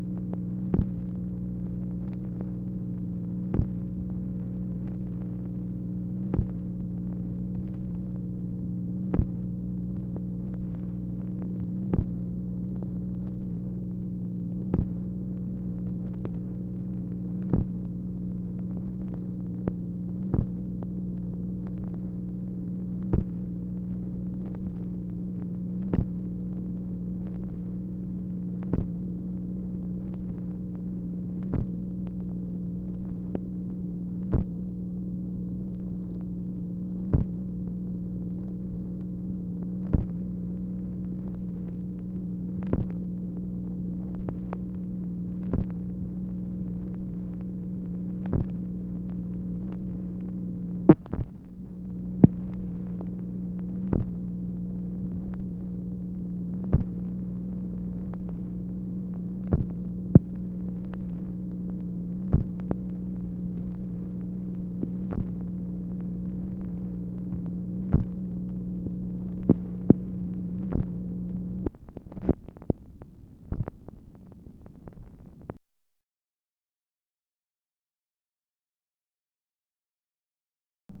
MACHINE NOISE, January 16, 1964
Secret White House Tapes | Lyndon B. Johnson Presidency